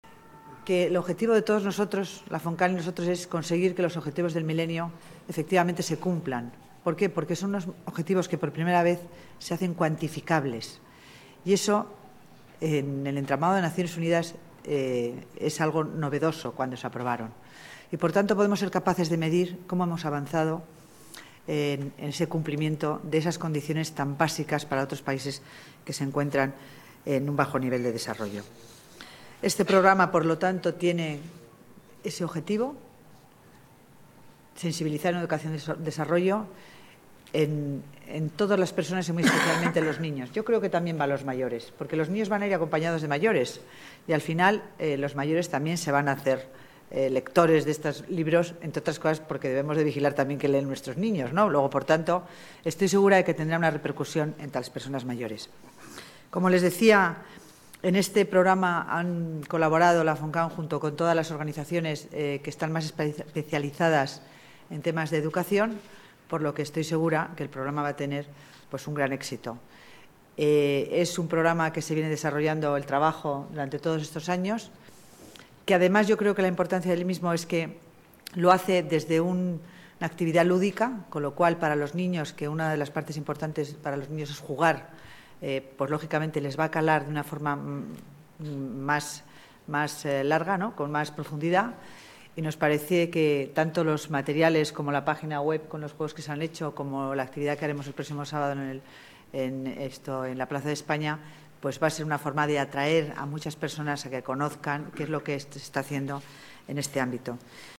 Nueva ventana:Declaraciones de la delegada de Familia y Servicios Sociales, Concepción Dancausa: Cooperación al Desarrollo